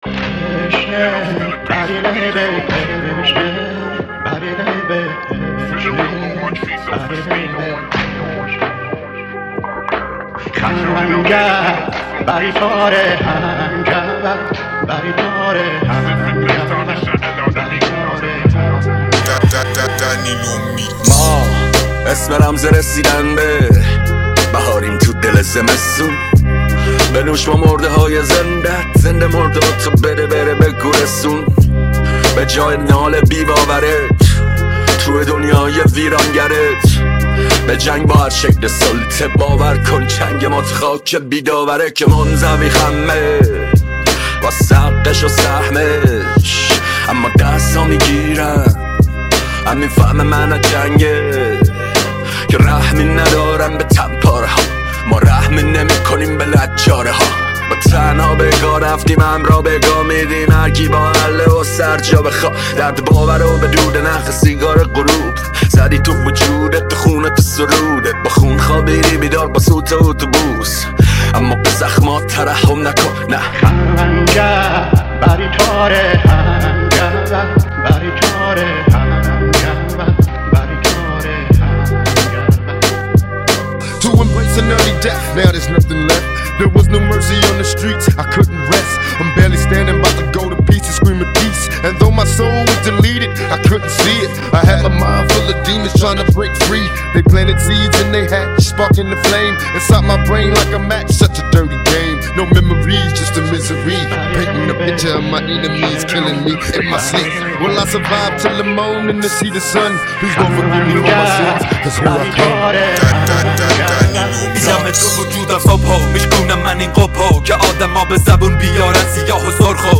ریمیکس رپی